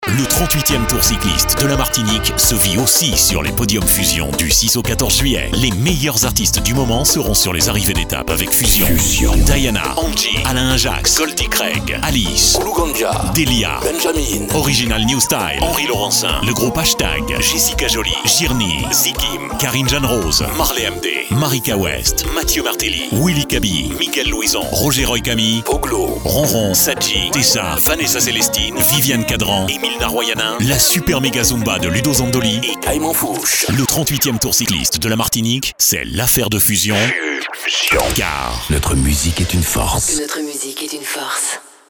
PROMO PODIUM TOUR CYCLISTE 2019.mp3 (1.6 Mo)
Pour avoir le nom des artistes cliquez sur le bouton de démarrage. Ecoutez la promo des podiums du Tour PROMO PODIUM TOUR CYCLISTE 2019.mp3 (1.6 Mo)